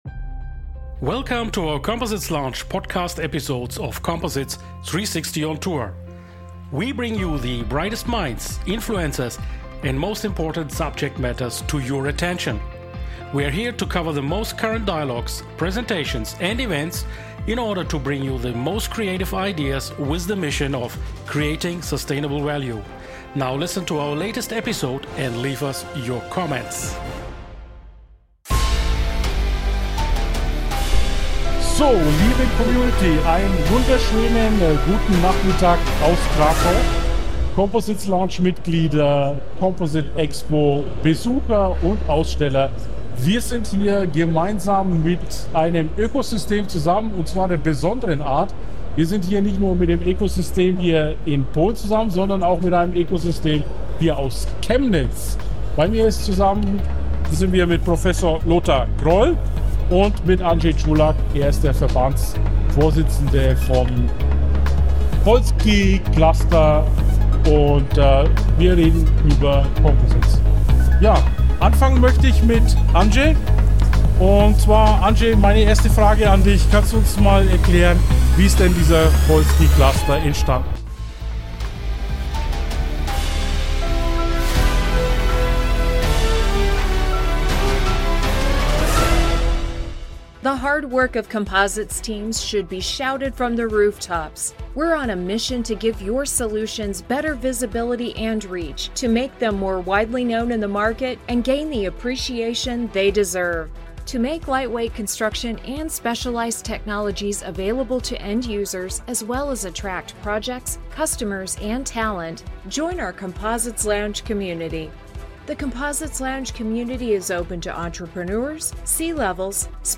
#180 Polski Cluster Composites Technologies und Technische Uni Chemnitz (Strukturleichtbau) im Interview ~ COMPOSITES LOUNGE - Das Online Experten Netzwerk Podcast